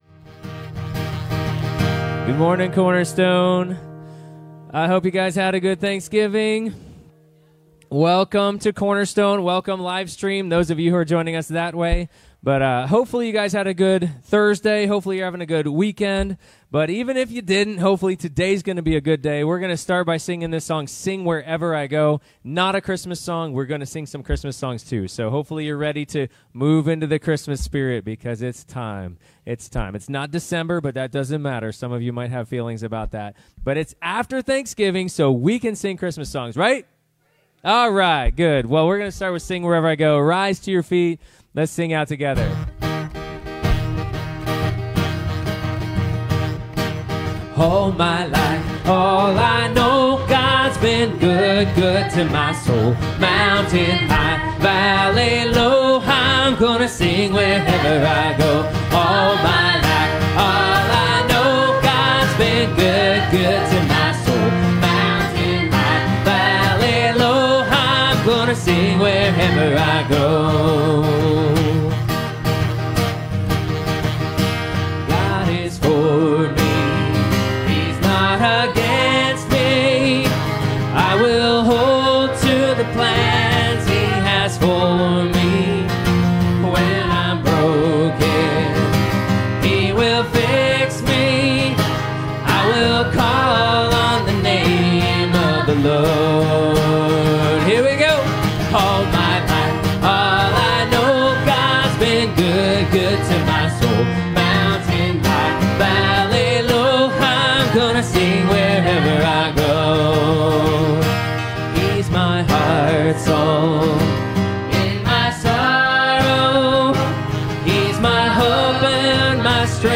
Passage: John15:18-27 Service Type: Sunday Morning